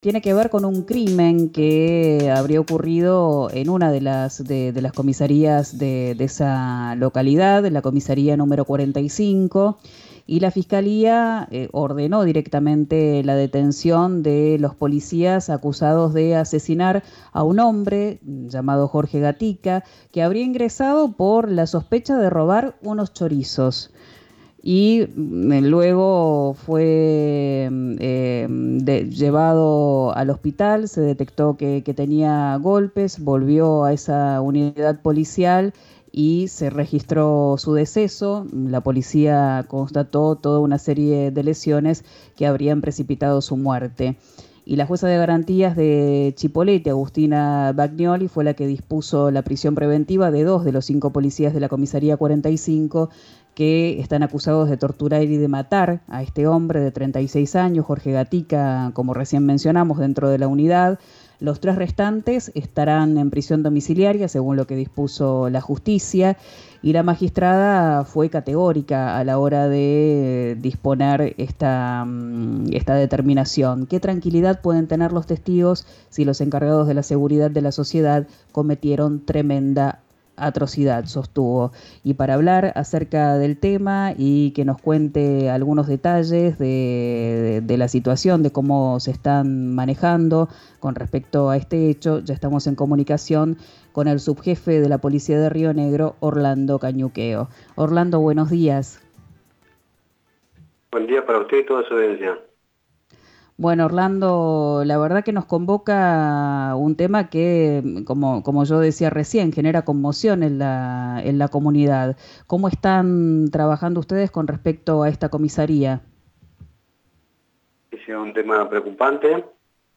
“ Esas prácticas tienen que ser desterradas de todo el personal policial «, dijo el subjefe de la policía de Río Negro, Orlando Cañuqueo , en diálogo con «Quién dijo verano», por RÍO NEGRO RADIO.